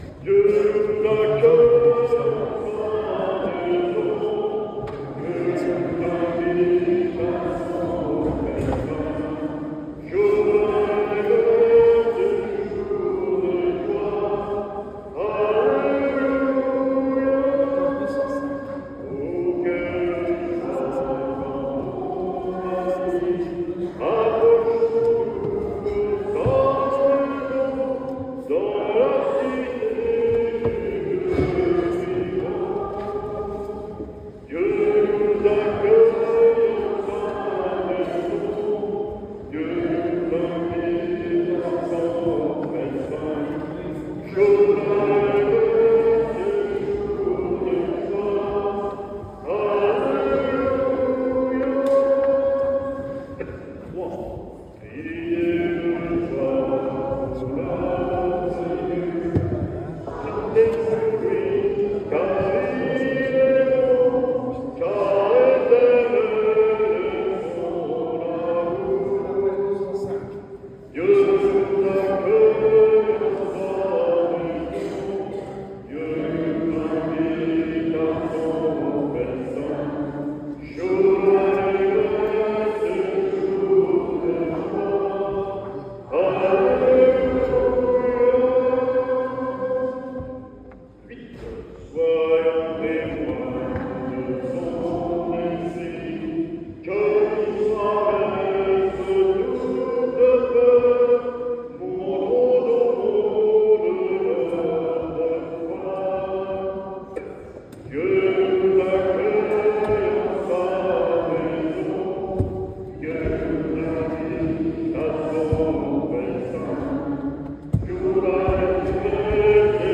Ecouter la Messe du 08/11/2025 pour les défunts du secteur de BRIOUX ==>>
sonnerie de cloches à la fin de la messe